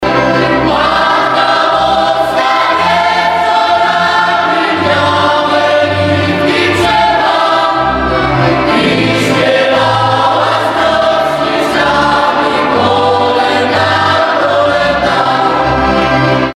Żywiołowo i radośnie grają, śpiewają, a ich muzyka udziela się słuchaczom.
Grupa zaśpiewa w sanktuarium w Chorzelowie najpiękniejsze kolędy i pastorałki.
Repertuar zespołu początkowo oparty jest na melodiach ludowych, z których stopniowo wzbogacał się o bardzo żywiołowe utwory folkowe inspirowane klimatami z regionu Beskidów, Podhala i Słowacji. Klimat muzyki wspiera bogate instrumentarium, gdzie obok skrzypiec, akordeonu i kontrabasu pojawiają się klarnety, trąbki, saksofony i haligonki.
Tagi: zespół Kolędy Chorzelów sanktuarium Głos Beskidu kapela ludowa koncert